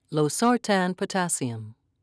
(lo-sar'tan)